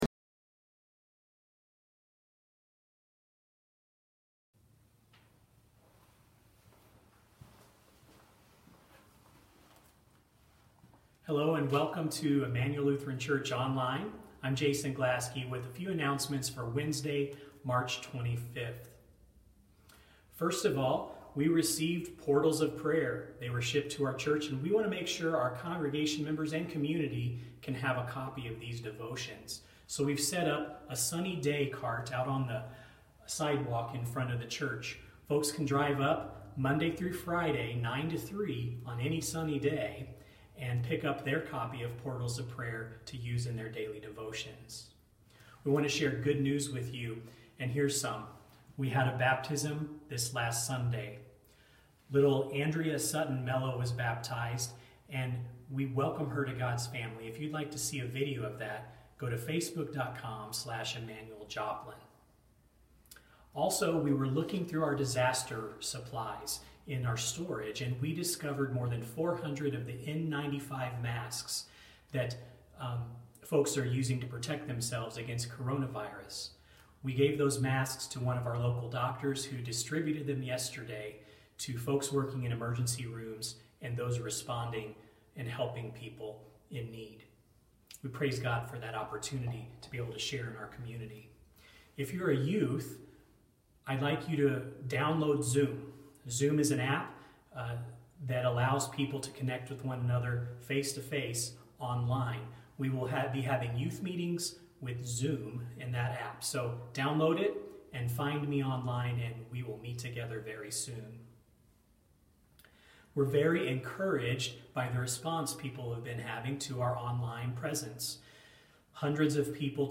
Murderous Eyes | Sermon for March 25, 2020 | Immanuel Lutheran Church LCMS